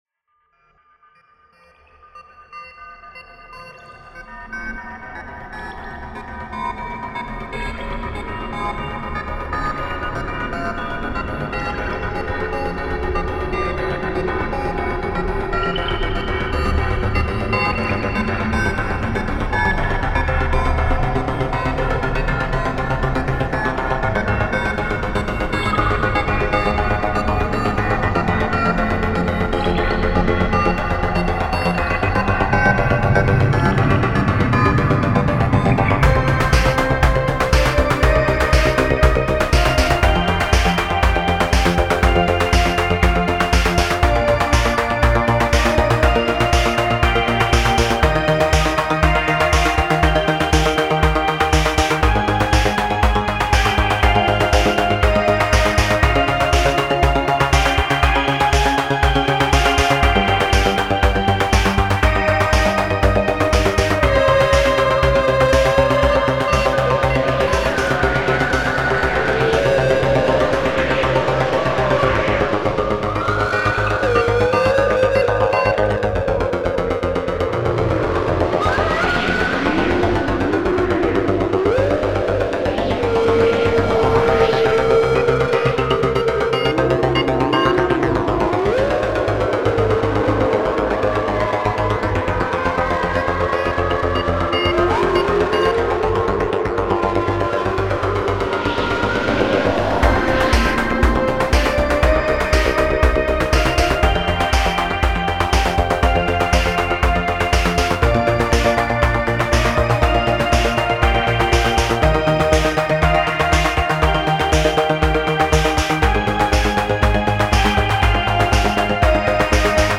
Композиция в стиле new age
Meditation Ambient Electronic New age Space Experimental